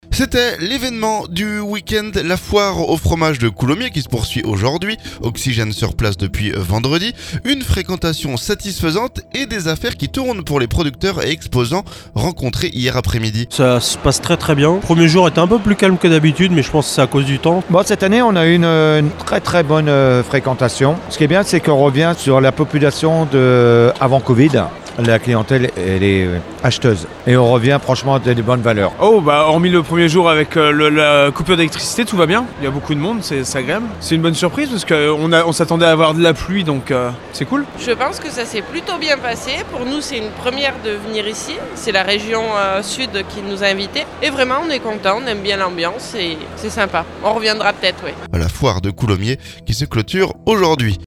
Oxygène sur place depuis vendredi. Une fréquentation satisfaisante et des affaires qui tournent pour les producteurs et exposants rencontrés dimanche après-midi.